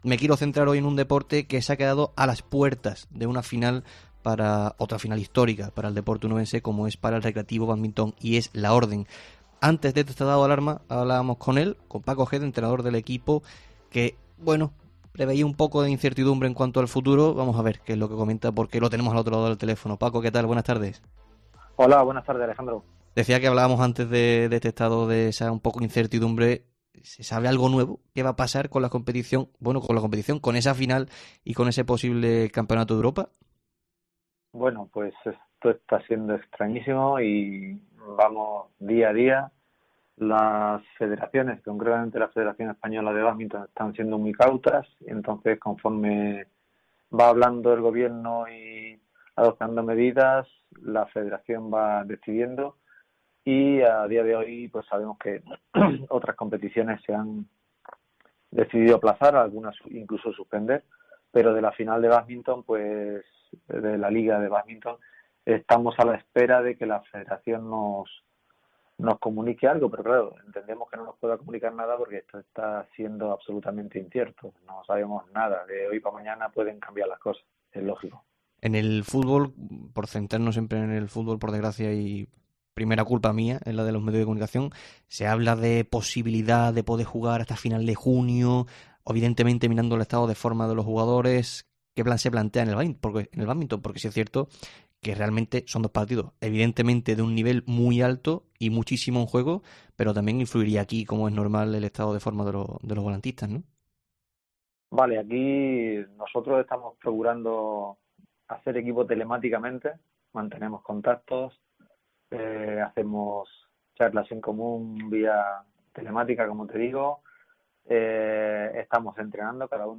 No está siendo una situación fácil para ningún deporte y en los clubes onubenses preocupa el futuro deportivo pero también económico.